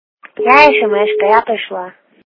При прослушивании Звук для СМС - Я СМС-ка, я пришла качество понижено и присутствуют гудки.